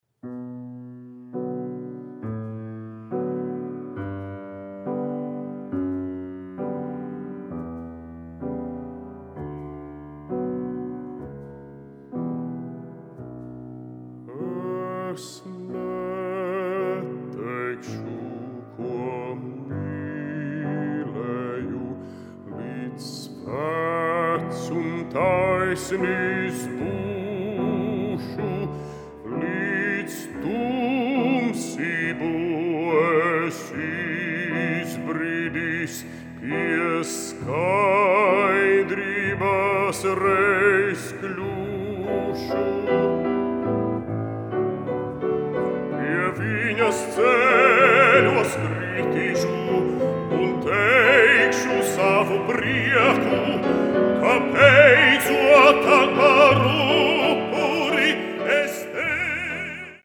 Žanrs: Vokālā kamermūzika
Instrumentācija: klavierēm, balsij